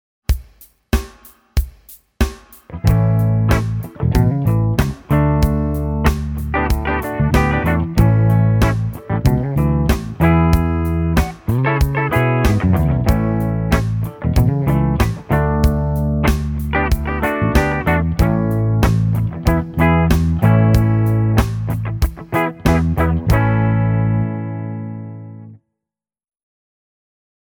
Here are some Kasuga soundbites:
Kasuga – bridge PU
kasuga-bridge-pu.mp3